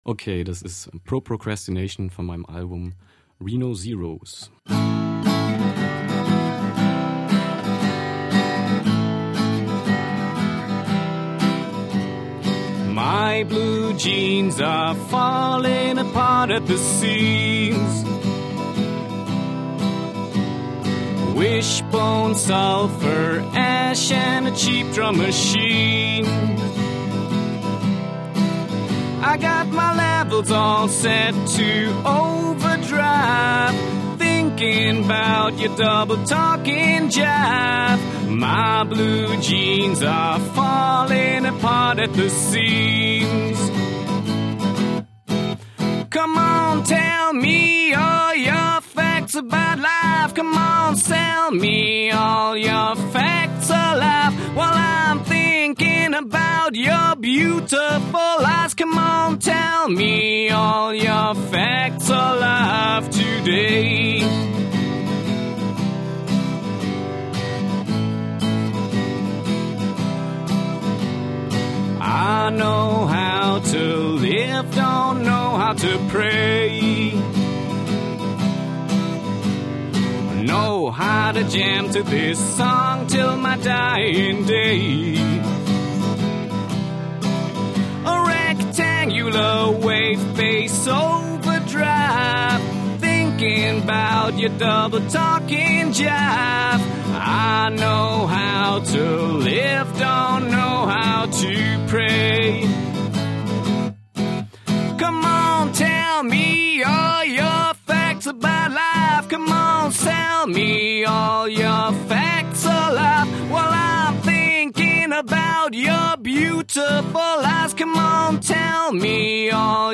Eine sehr schöne Unplugged Version